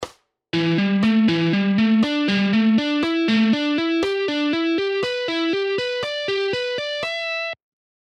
Same lick in half Speed:
Half-Speed-Pentatonic-Scale-Guitar-Licks-3.mp3